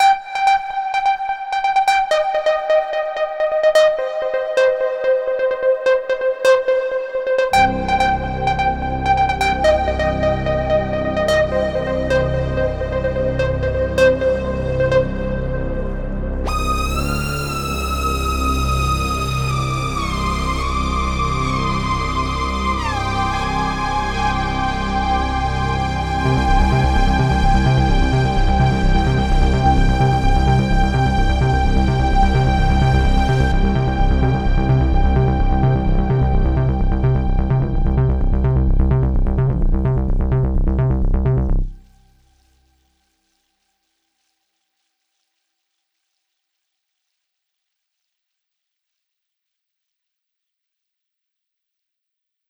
Если что в примере никакой внешней обработки - просто два голых пресета из архива